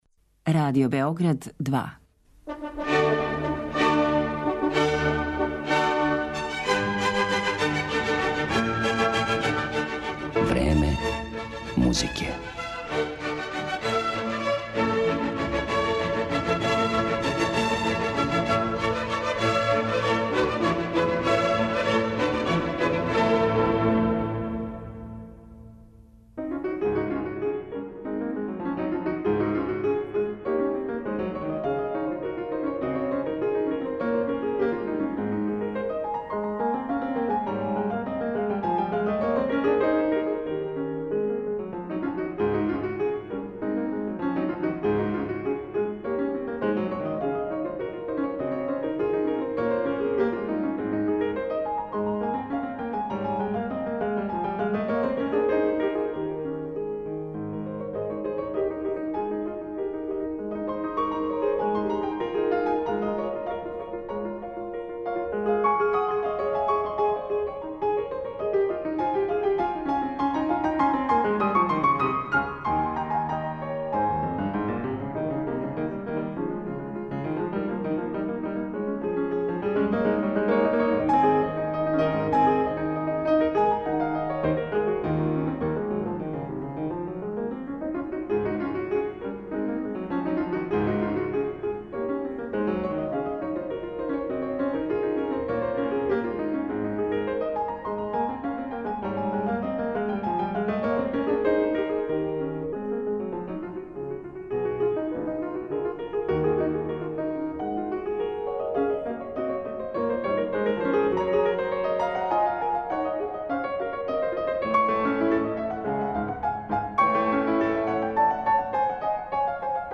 У покушају да умањимо ту историјску неправду, данашње Време музике посветили смо клавирским композицијама Сесил Шаминад.